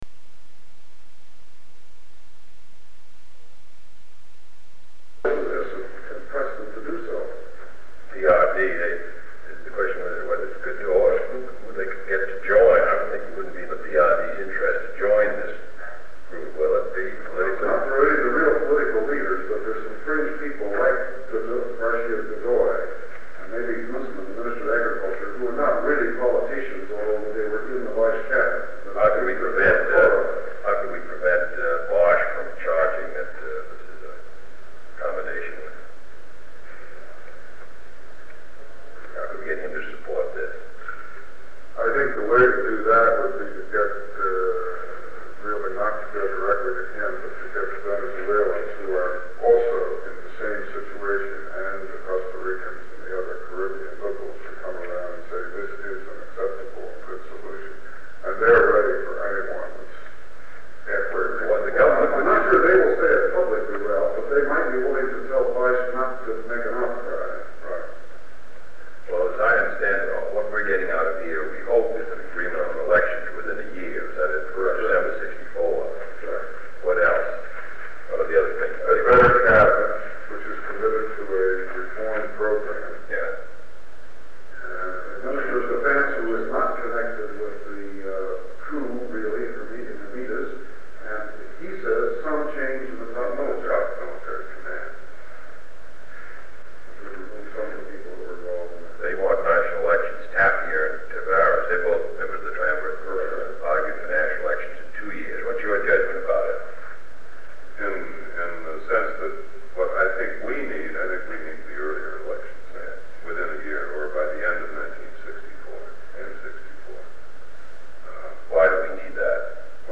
Sound recording of two meetings held on November 5, 1963.
Secret White House Tapes | John F. Kennedy Presidency Meetings: Tape 119/A55.